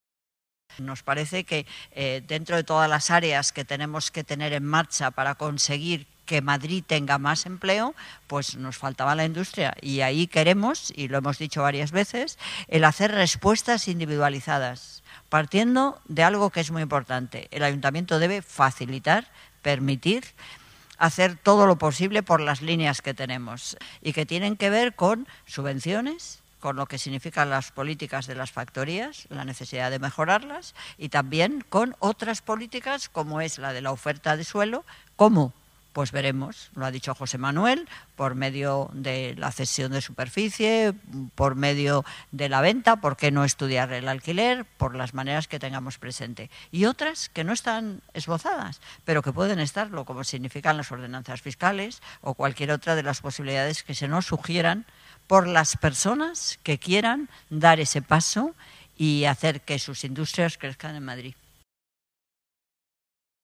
Presentación estrategia
Manuela Carmena habla sobre la importancia de la industria para que Madrid tenga más empleo